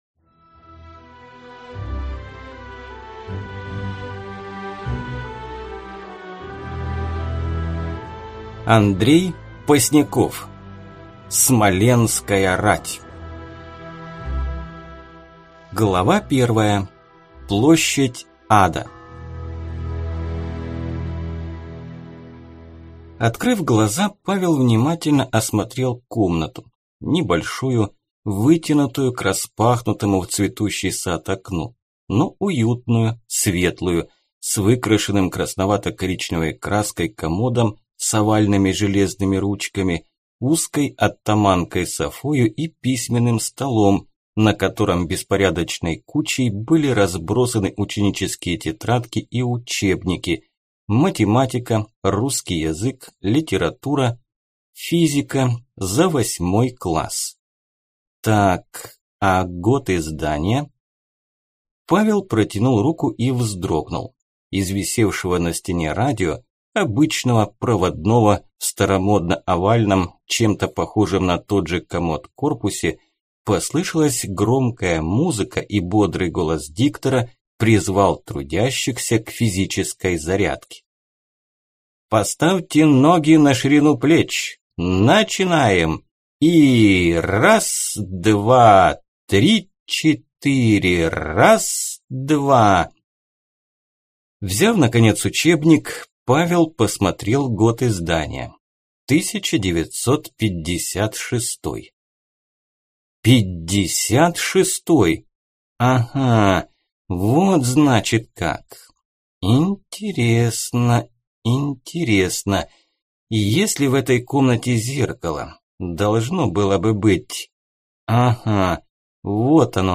Аудиокнига Боярин: Смоленская рать. Посланец. Западный улус | Библиотека аудиокниг